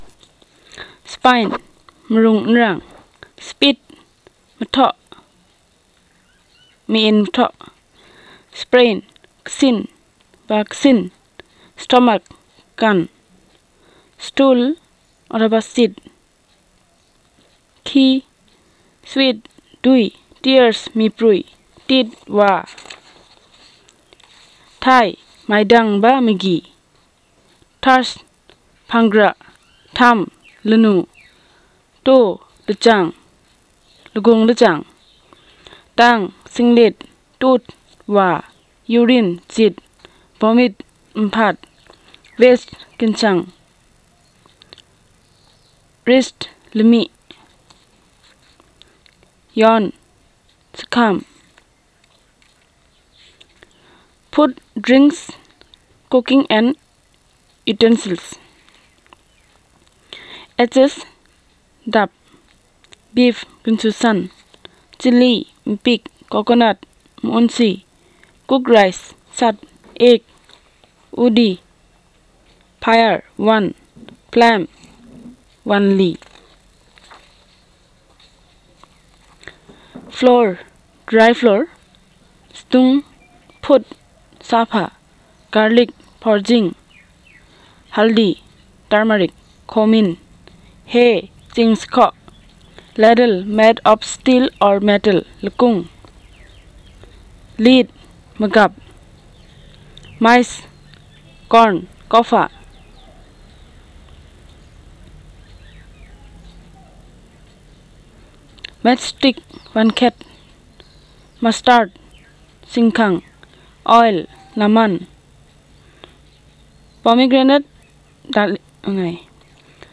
NotesThis is an elicitation of words about human body parts, ailments, bodily condition and function, food, drinks, cooking and utensils.